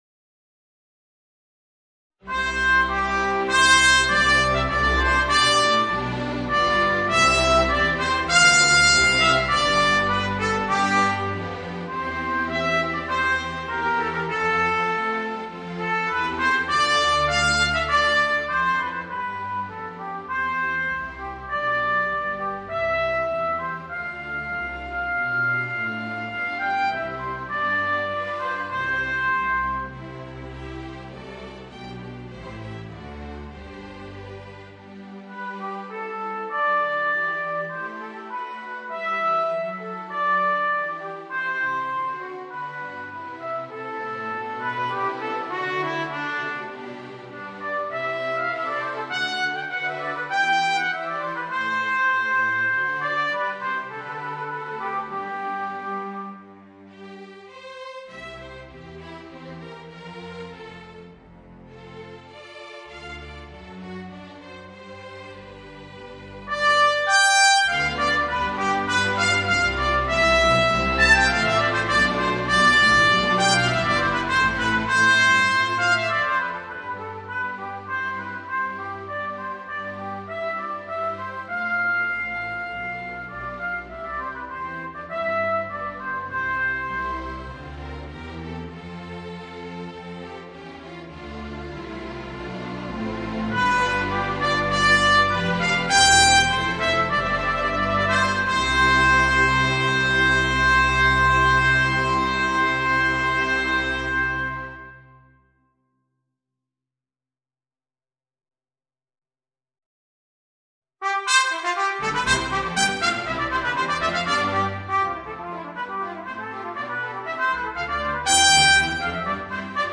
Voicing: Trumpet Solo